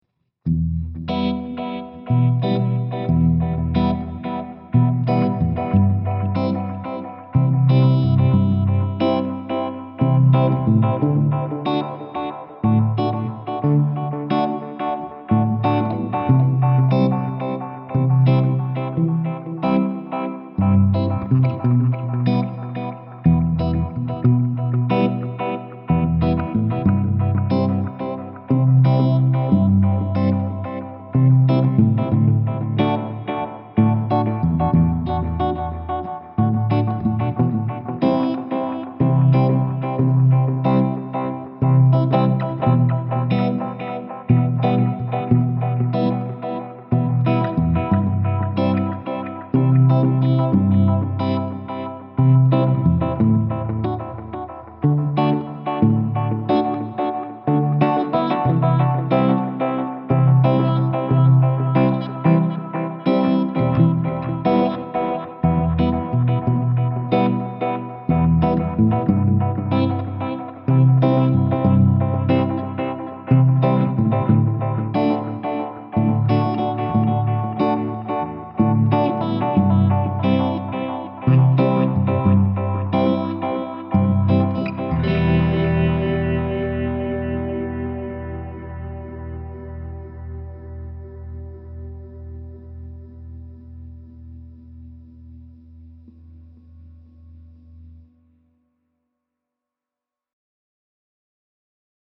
podXT-blackfacedub-v1.mp3.mp3